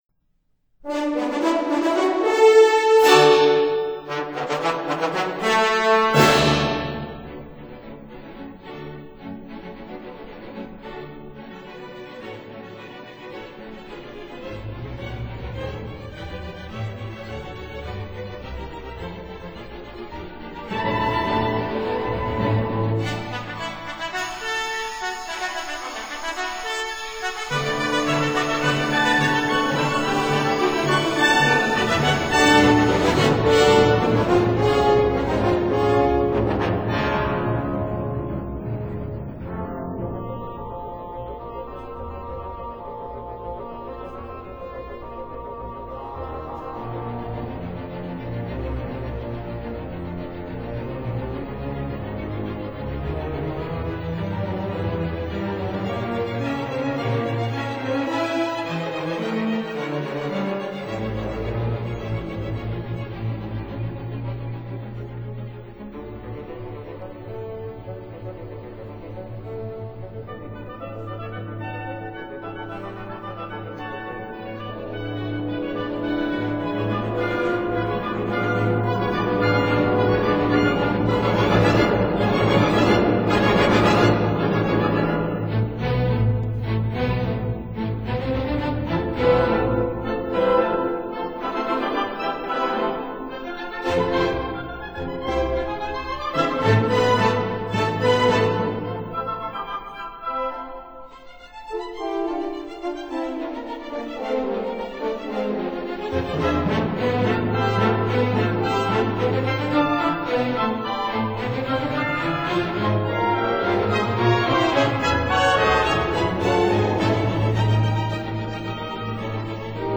他的音樂風格是晚浪漫主義的。
violin
cello